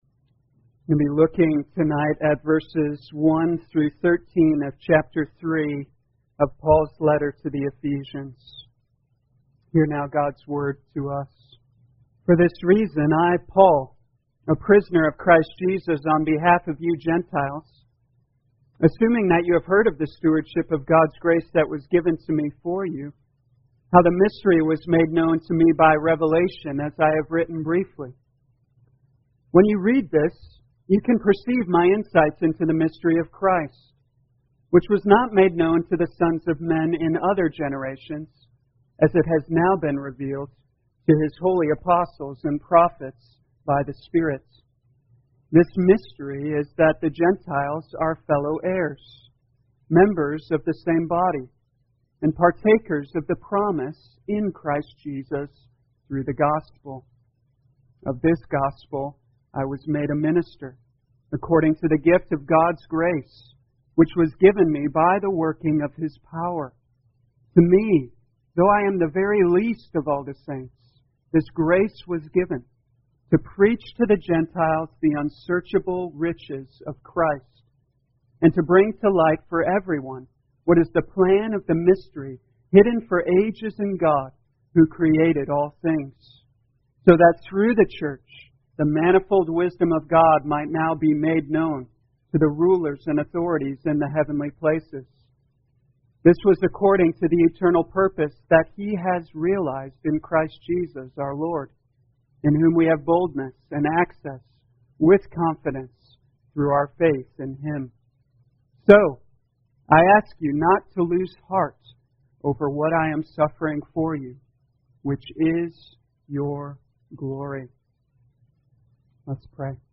2020 Ephesians Evening Service Download